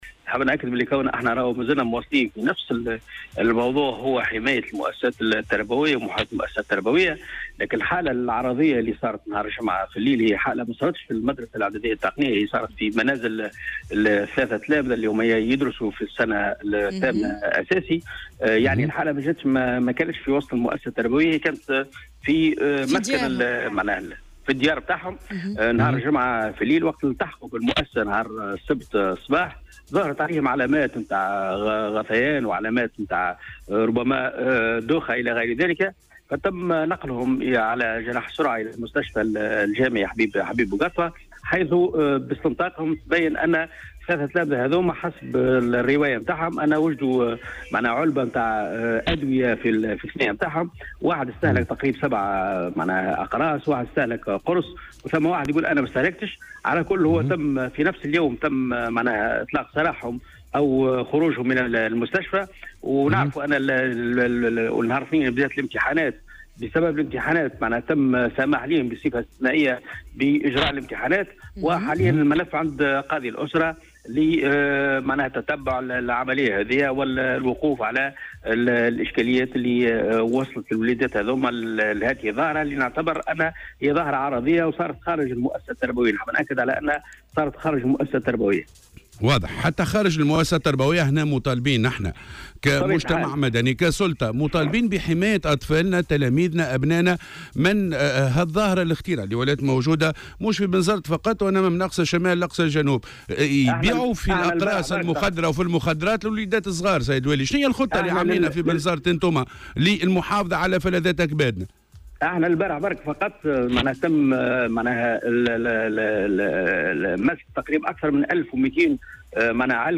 أكد والي بنزرت، محمد قويدر في مداخلة له اليوم في برنامج "صباح الورد" على "الجوهرة أف أم" ما تم تداوله من أخبار حول تناول عدد من التلاميذ بالجهة لحبوب مخدرة ودخولهم في حالة هستيريا.